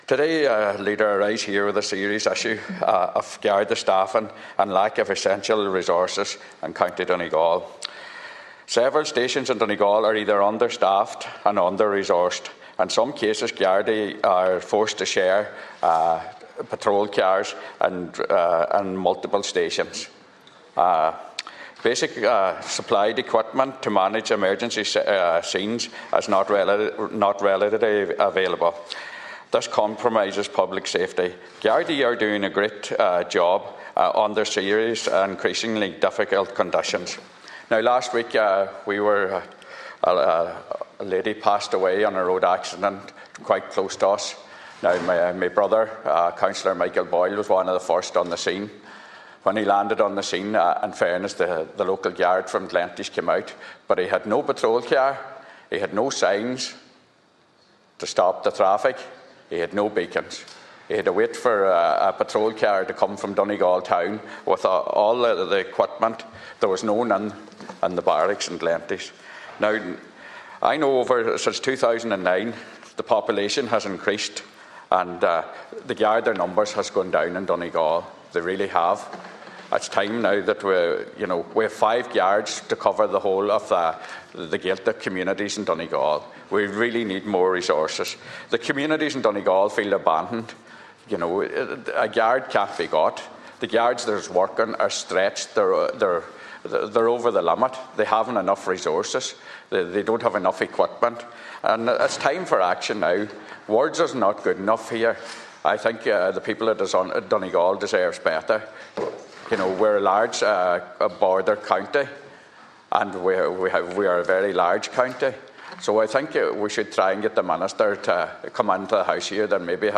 Senator Manus Boyle raised the issue in the Seanad, saying that because stations in Donegal are forced to share resources, the Garda was left under significant pressure while managing the crash scene.